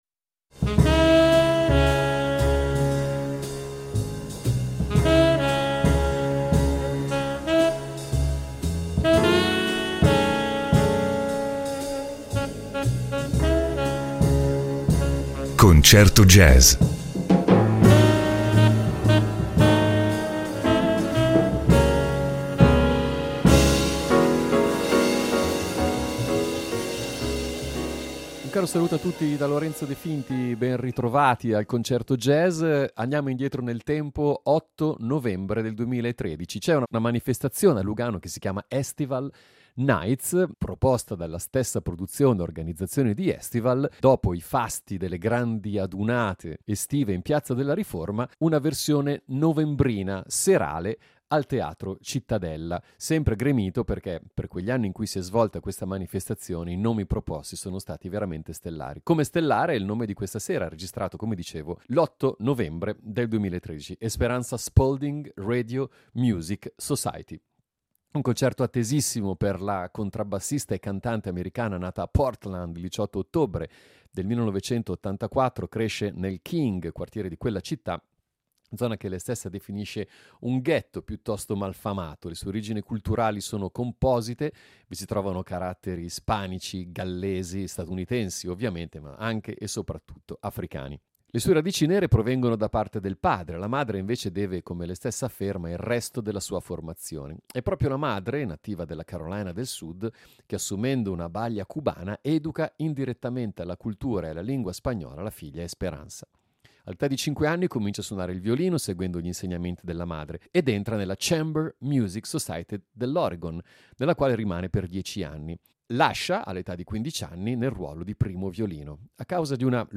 bassista e cantante